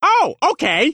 Worms speechbanks
orders.wav